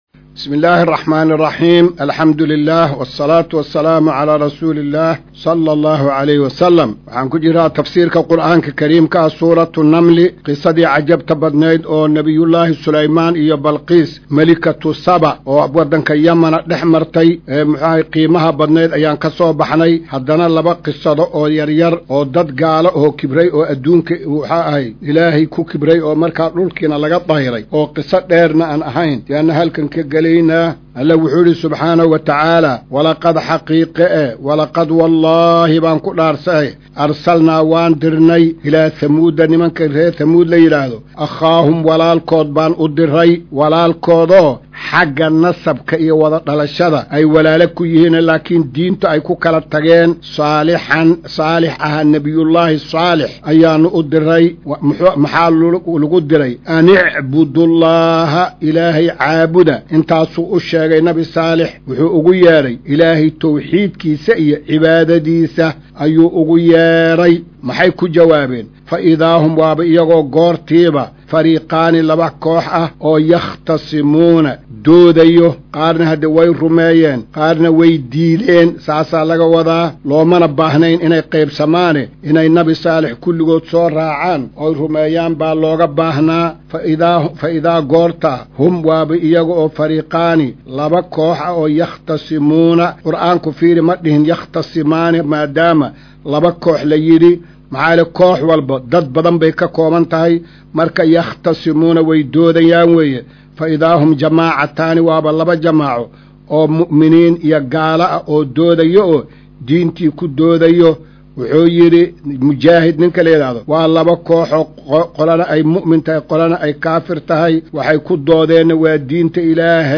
Maqal:- Casharka Tafsiirka Qur’aanka Idaacadda Himilo “Darsiga 183aad”